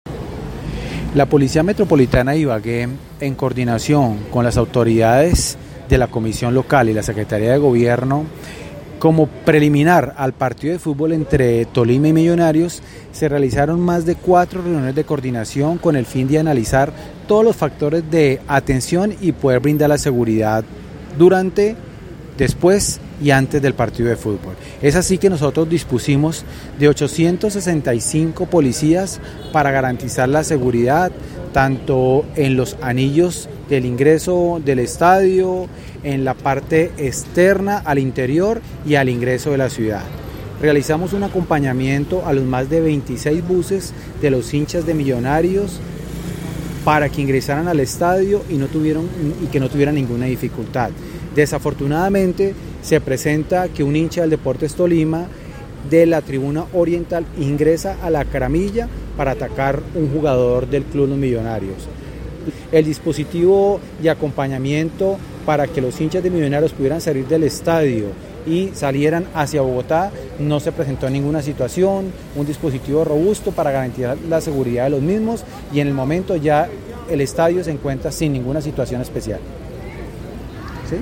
El hecho de violencia se dio cuando un hombre ingresó a la gramilla por el lado de la tribuna Oriental y agrede al jugador de Millonarios, luego del incidente de violencia, se dispuso de una escolta para acompañar en la salida del estadio a los jugadores de Millonarios, según declaraciones del Coronel Carlos Germán Oviedo, comandante de la Policía Metropolitana de Ibagué (Metib).